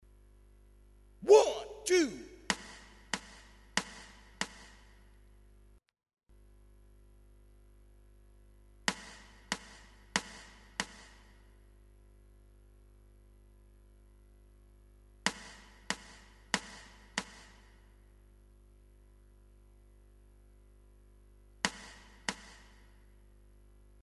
Tutti i nostri dettati, fino alle modulazioni sono stati registrati sopra un ritmo di metronomo che pulsa nelle unità di tempo per le misure semplici e nelle suddivisioni per le misure composte.